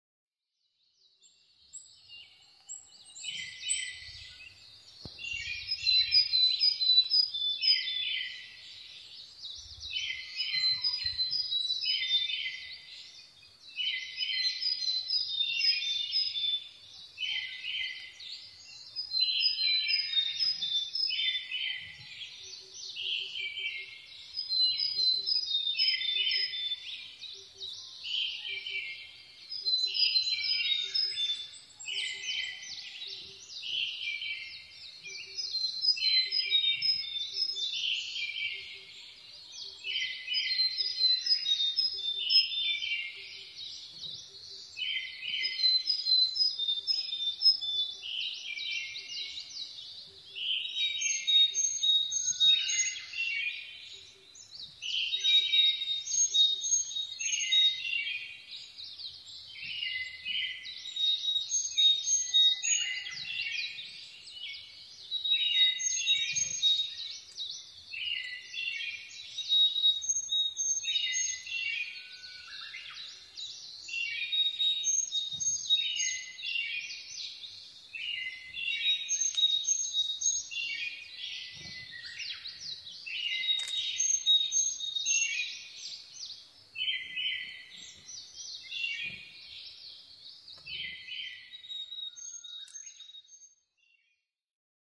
コガラ　Parus montanusシジュウカラ科
日光市土呂部　alt=1240m
MPEG Audio Layer3 FILE 128K 　1'34''Rec: SONY PCM-D50
Mic: Panasonic WM-61A  Binaural Souce with Dummy Head
他の自然音:　アカハラ・アオバト・ウグイス・ツツドリ